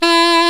Index of /90_sSampleCDs/Roland L-CD702/VOL-2/SAX_Alto Short/SAX_Pop Alto
SAX D#3 S.wav